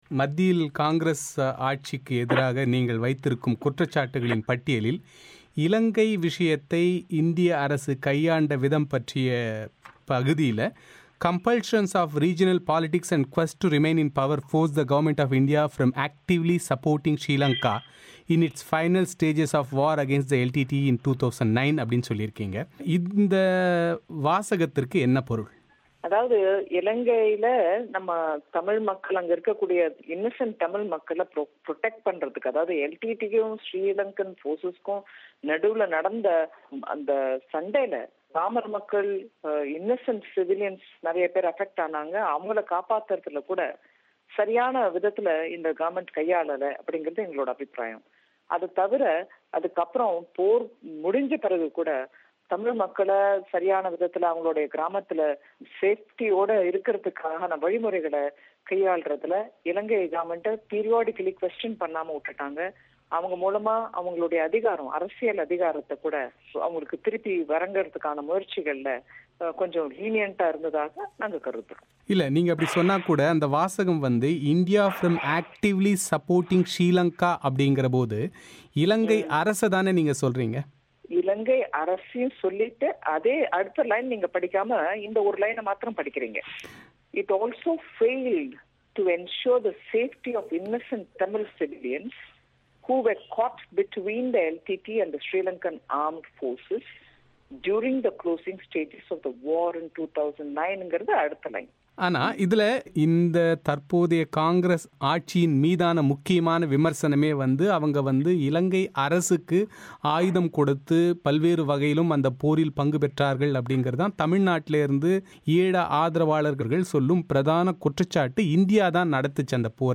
இலங்கையில் 2009 ஆம் ஆண்டு நடந்த இறுதிப்போரின்போது இந்தியாவை ஆளும் காங்கிரஸ் தலைமையிலான மத்திய அரசு, உள்ளூர் அரசியல் காரணங்களினால் இலங்கையை கூடுதலாக ஆதரிக்கவில்லை என பாஜக குற்றம் சாட்டியிருப்பதன் பொருள் என்ன என்பது குறித்து பாஜக செய்தித்தொடர்பாளர் நிர்மலா சீதாராமனின் செவ்வி.